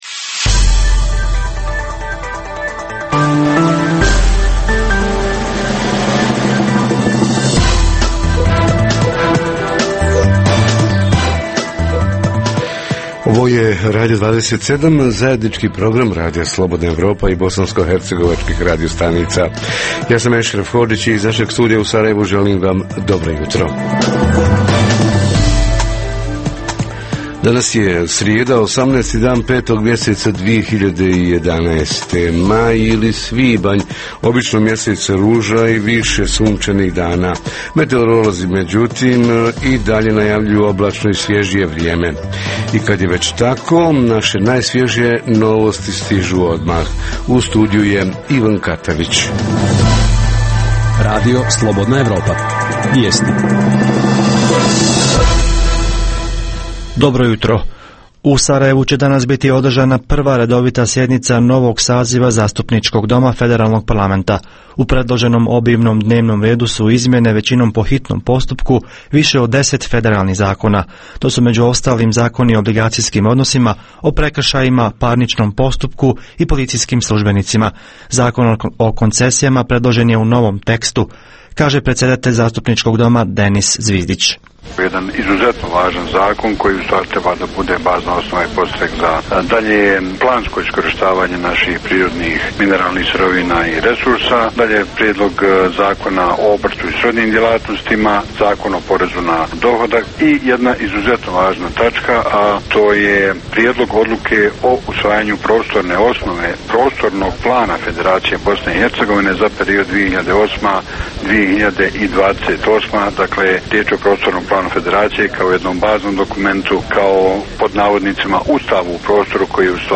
Vjeronauka u predškolskim ustanovama i u osnovnim školama – kako je organizirana – ko je i zašto protiv, a ko je za? Reporteri iz cijele BiH javljaju o najaktuelnijim događajima u njihovim sredinama.